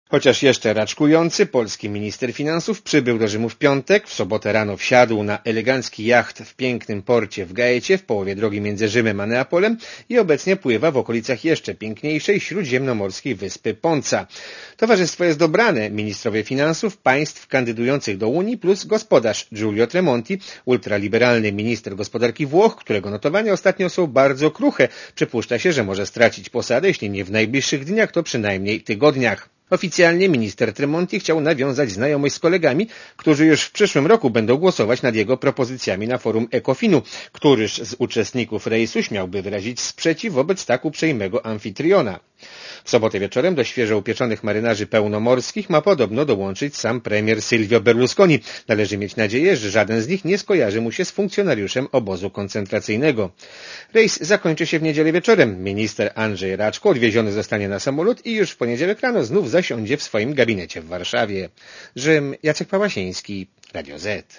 Posłuchaj relacji korespondenta Radia Zet (250 KB)